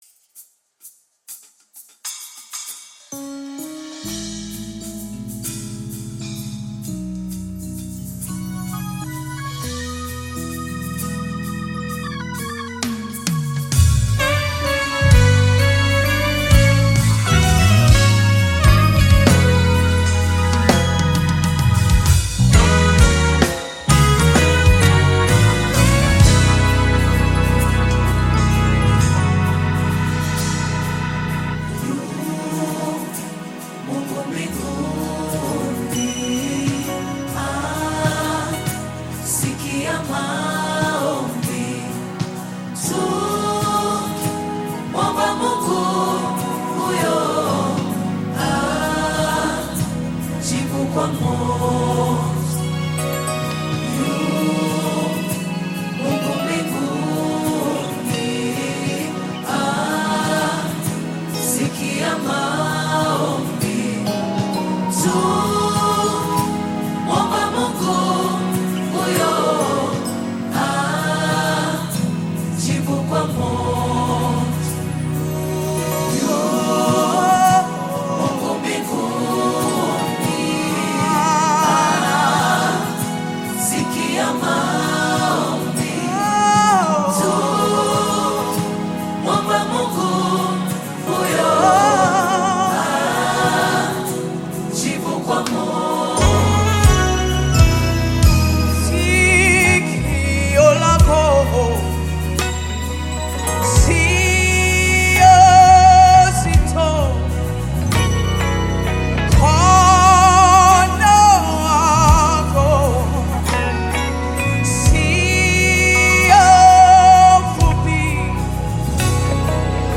Gospel music track
Tanzanian gospel group